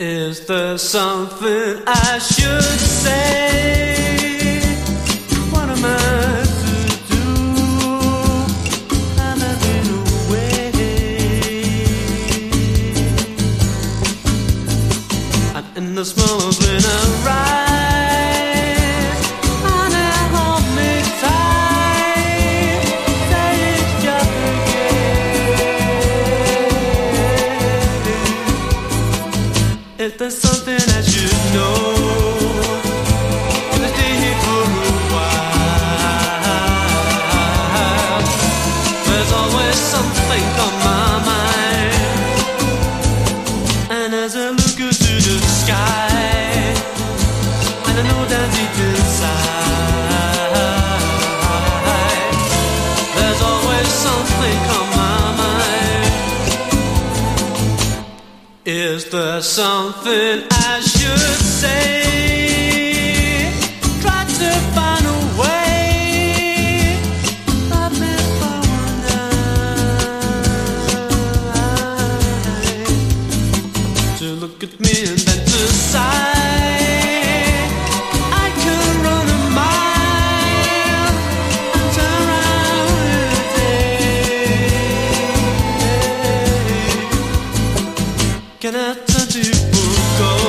ギター・カッティングに哀愁漂うホーンとストリングスが絡んでいく